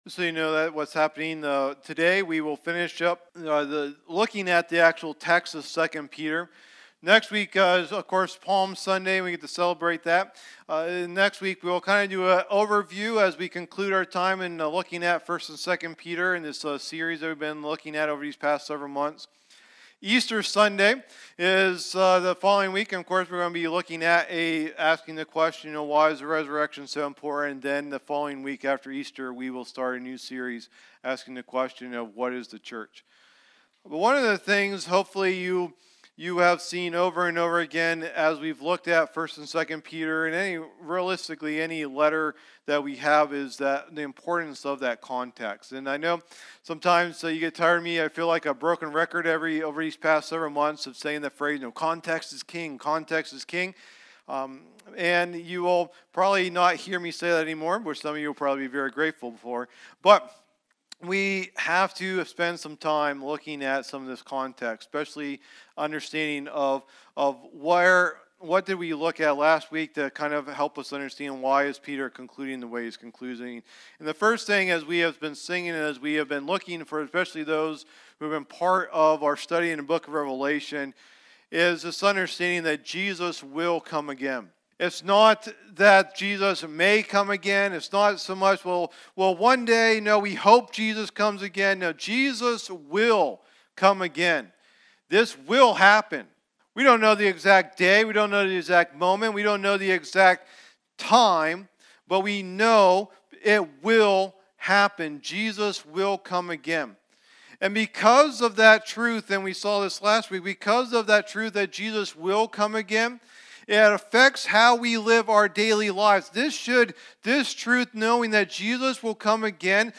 Download Download Reference 2 Peter 3:14-18 Sermon Notes 2 Peter 3.14-18.pdf Message #10 of the "2 Peter" teaching series 2 Peter Unashamed!